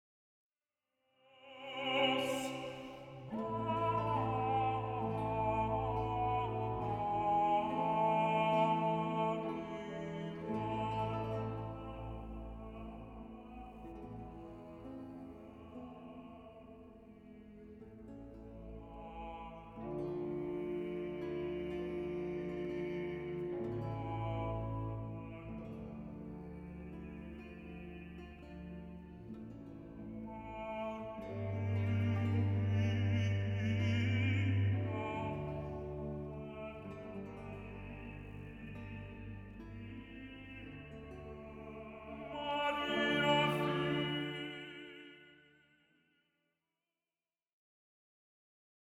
Un manifeste flamboyant de la révolution baroque